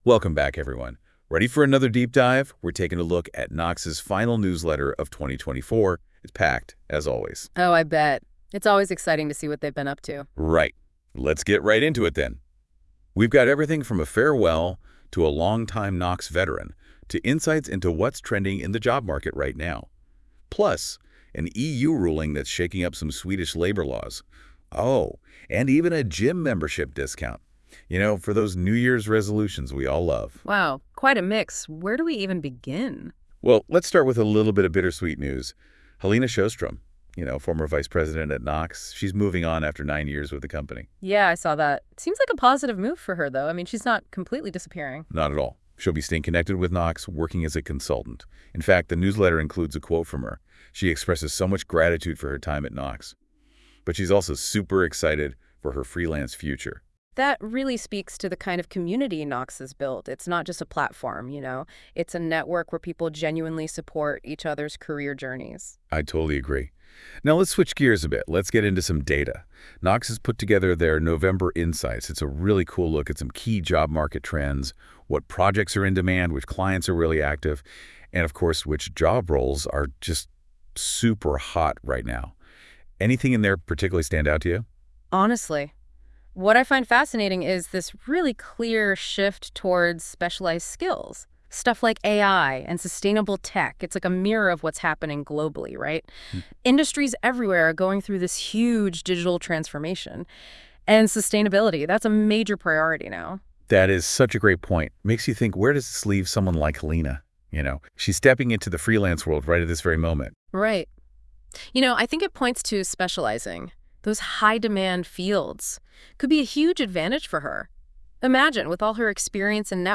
Här kan du lyssna på NOX Nyhetsbrev powered by NotebookLM. Podcasten baseras på innehållet i nyhetsbrevet men är helt genererad av AI och kan självklart därför sväva iväg lite.